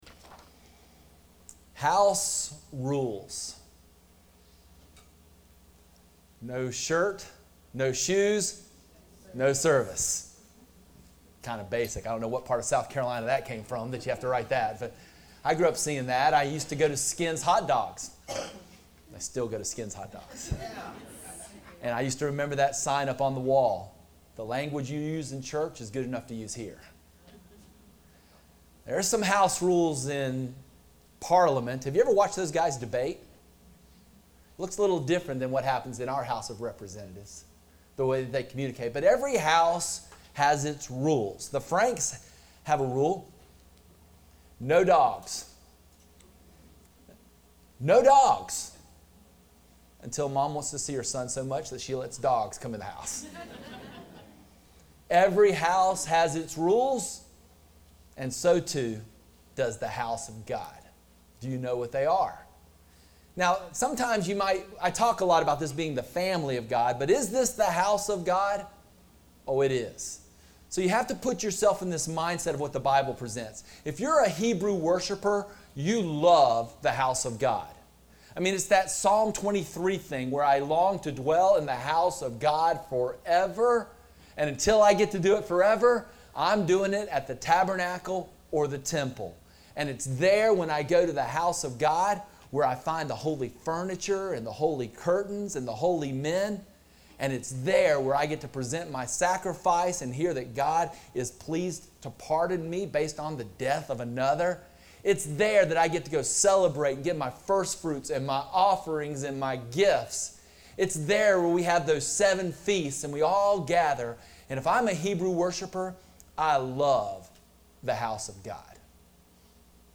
Horizon Church Sermon Audio